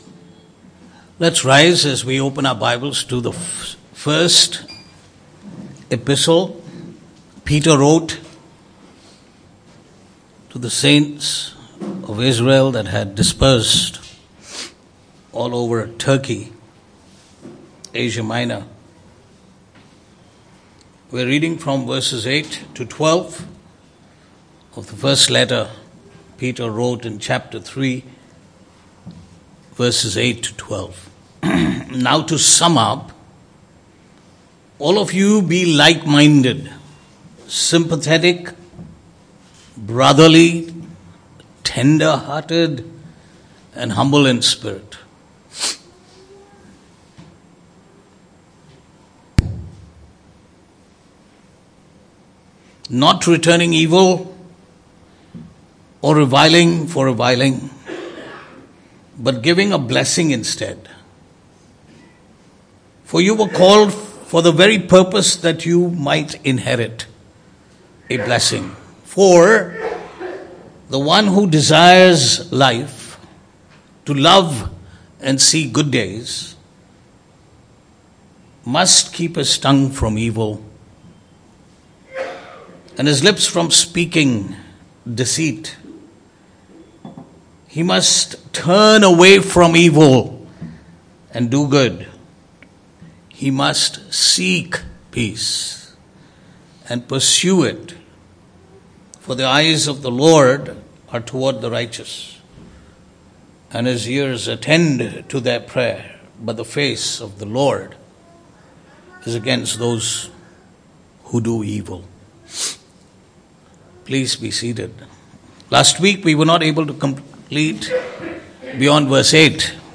Passage: 1 Peter 3:8-12 Service Type: Sunday Morning